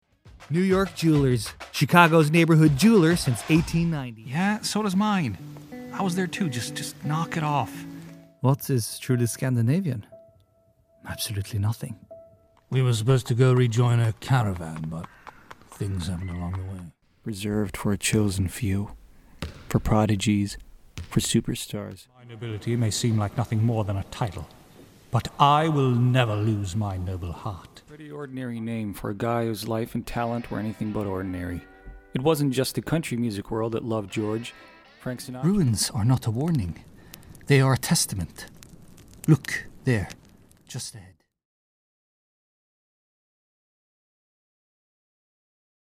Animation
Commercial
Documentary
Video Games